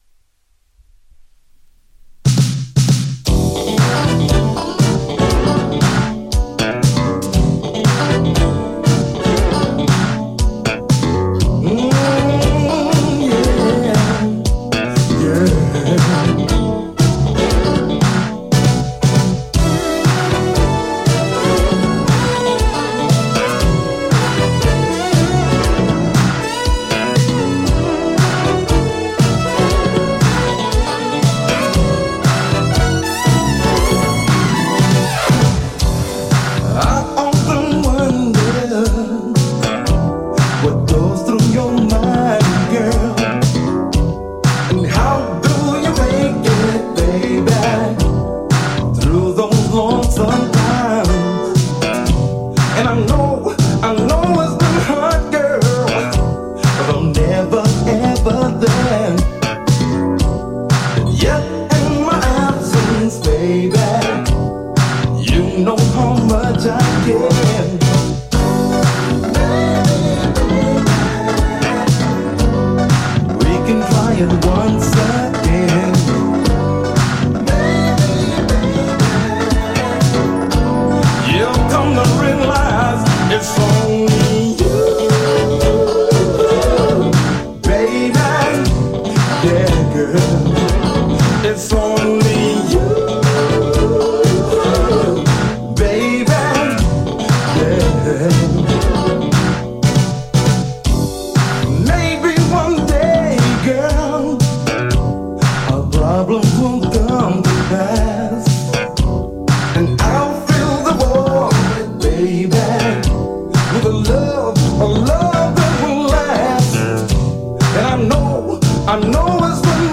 (DANCE SIDE)
ジャンル(スタイル) DISCO / FUNK / SOUL